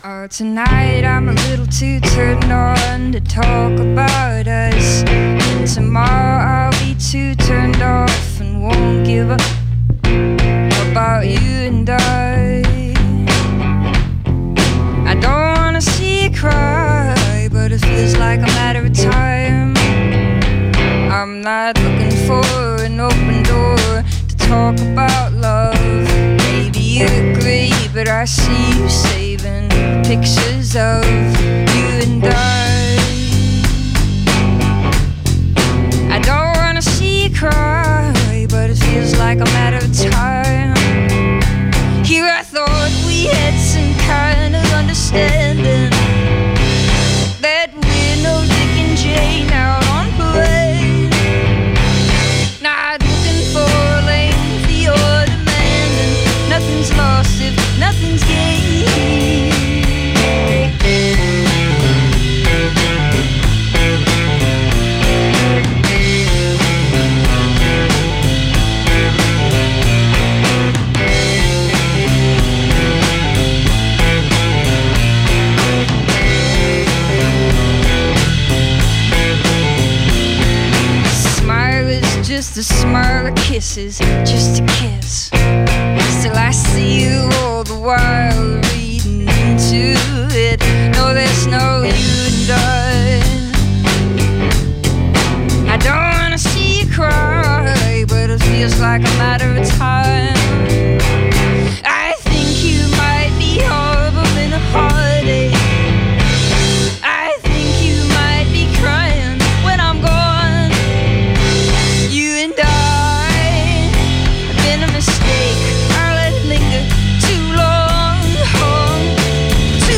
Audacious reinvention of the magnetic kind.
to close out the weekend on a soft and luxuriant note